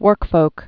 (wûrkfōk) also work·folks (-fōks)